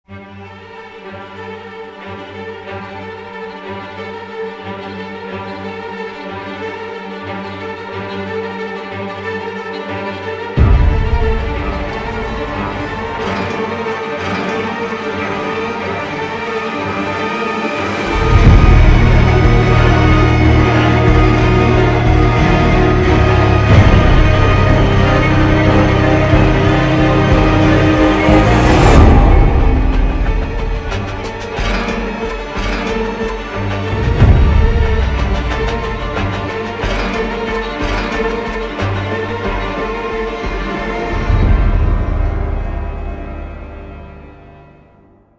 саундтрек, заставка
OST